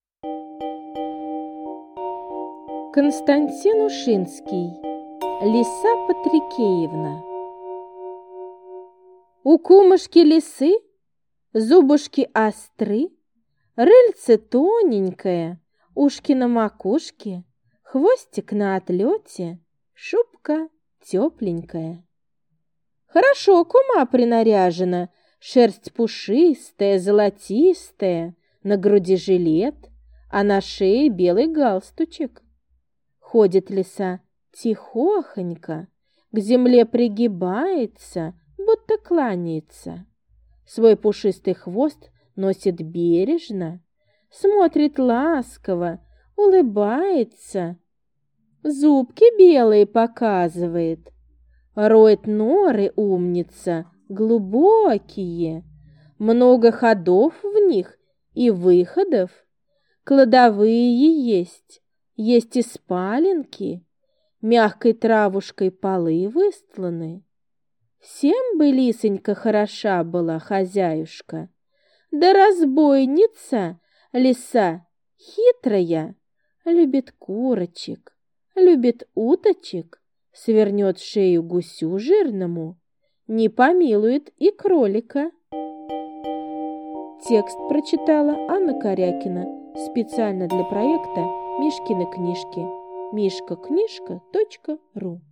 Лиса Патрикеевна - аудио рассказ Ушинского - слушать онлайн